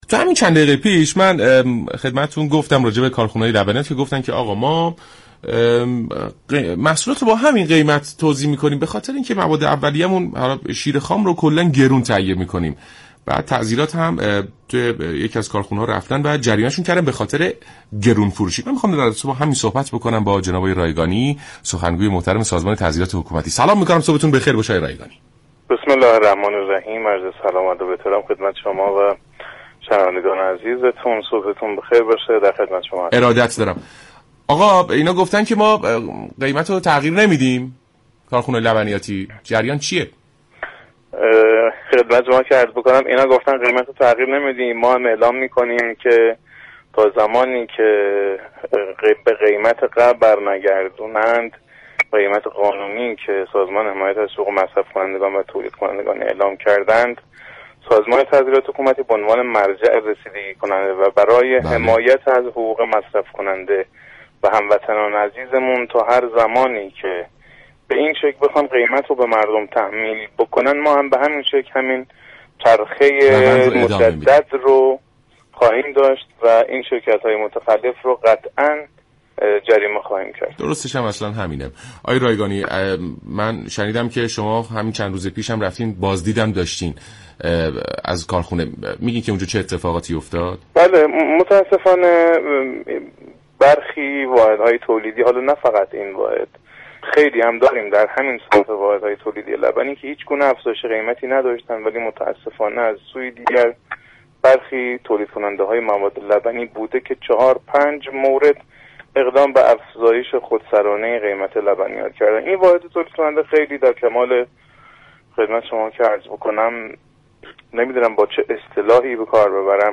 در گفت و گو با برنامه «سلام صبح بخیر» رادیو ایران